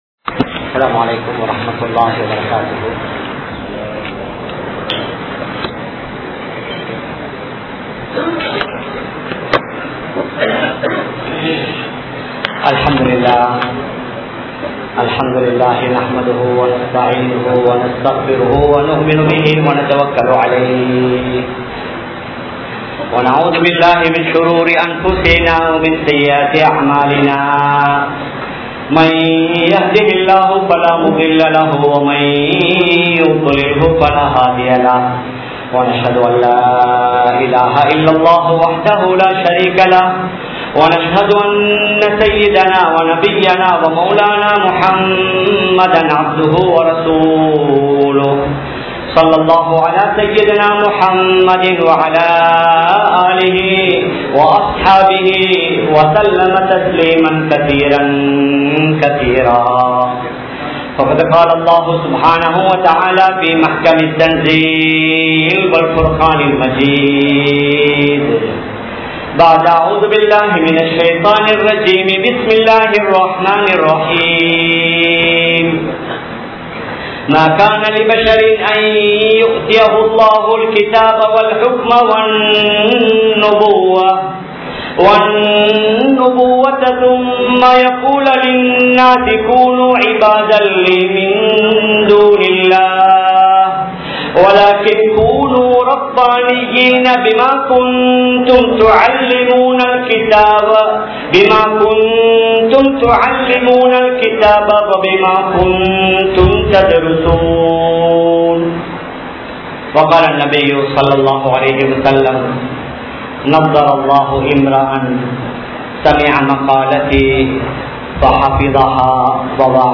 Aanmeeha Arivai Valarthukollugal (ஆண்மீக அறிவை வளர்த்துக் கொள்ளுங்கள்) | Audio Bayans | All Ceylon Muslim Youth Community | Addalaichenai